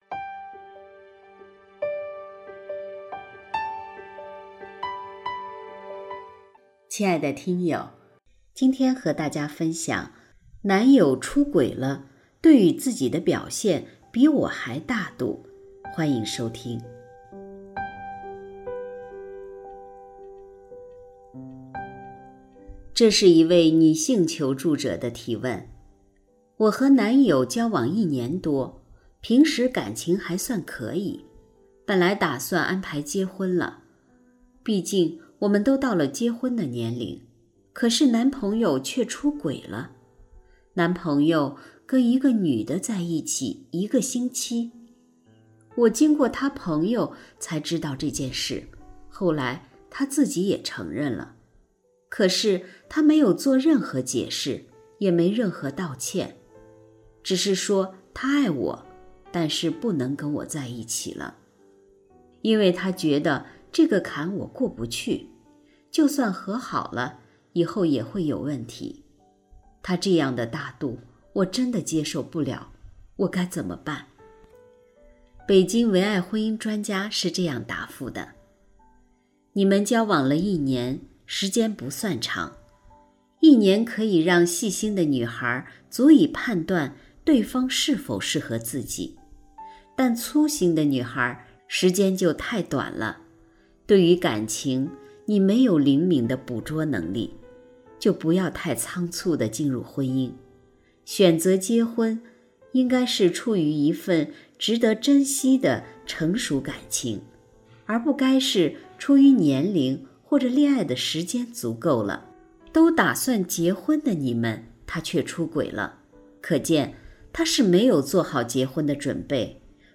首页 > 有声书 > 婚姻家庭 > 单篇集锦 | 婚姻家庭 | 有声书 > 男友出轨了对于自己的表现比我还大度